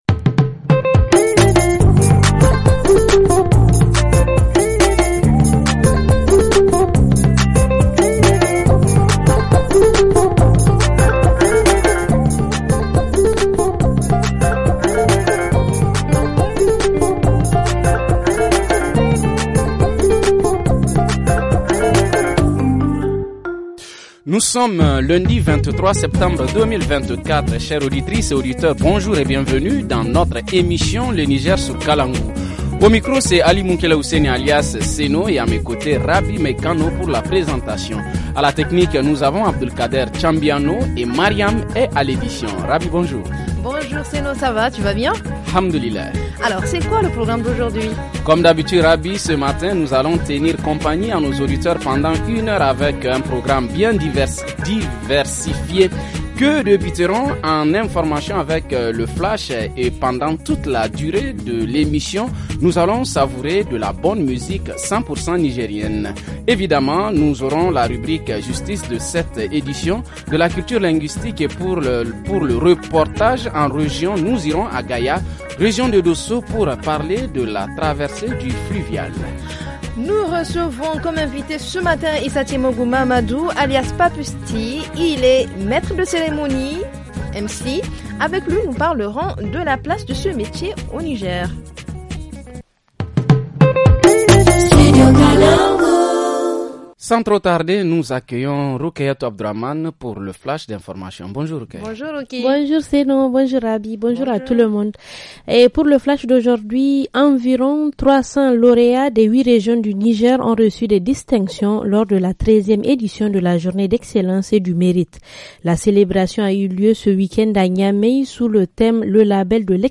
1. Le titre « Sarki Abzine », de la troupe des jeunes filles de Tchirozérine